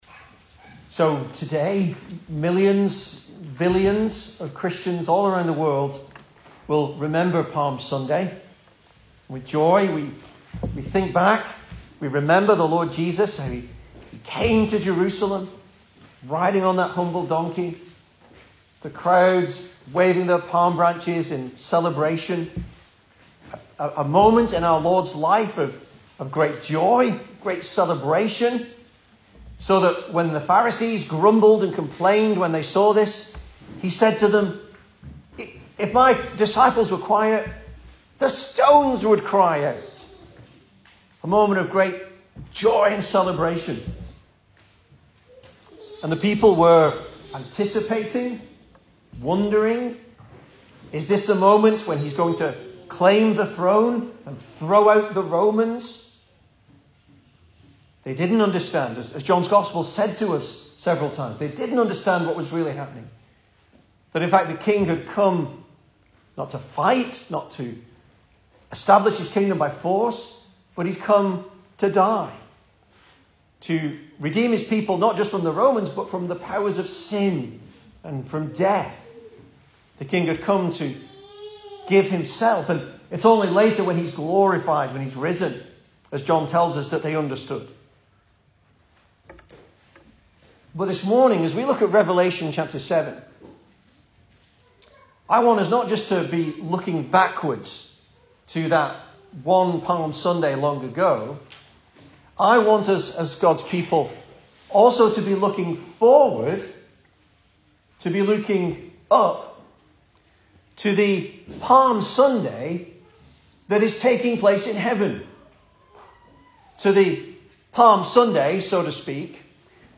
Service Type: Sunday Morning
Easter Sermons